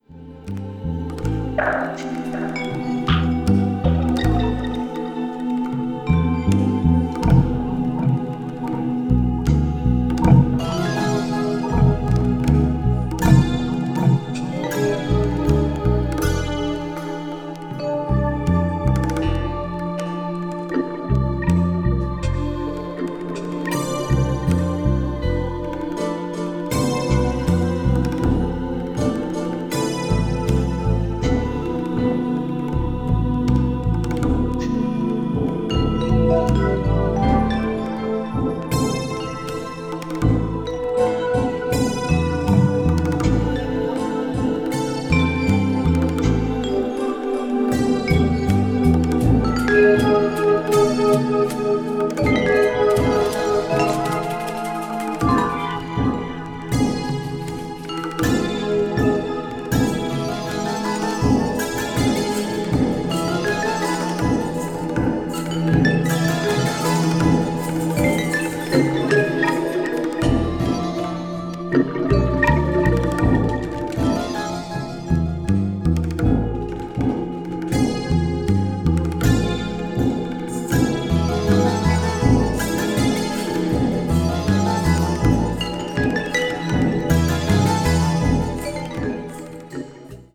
media : EX-/EX(わずかにチリノイズが入る箇所あり,A1先頭:再生音に影響ない7mm程のキズ1本あり)